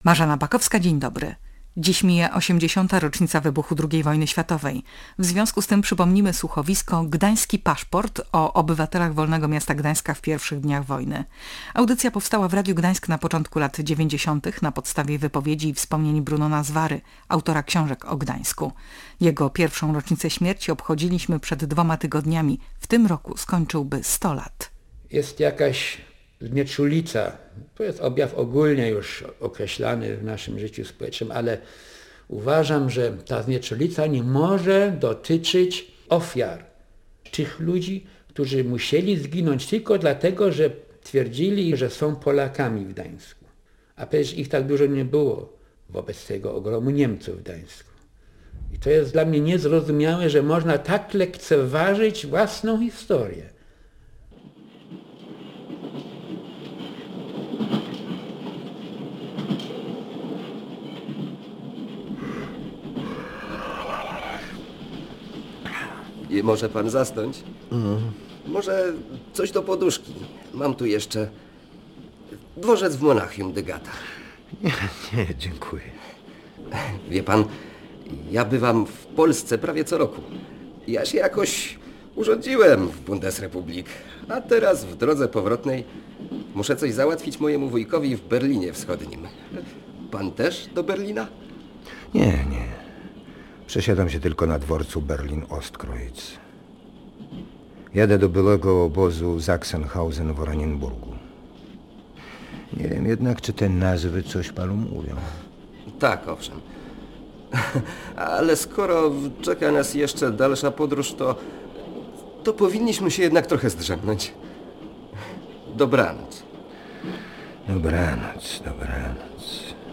Słuchowisko, które znajduje się w archiwum Radia Gdańsk, jest więc wyjątkową okazją do ponownego oddania mu głosu i zaświadczenia o losach gdańskich Polaków.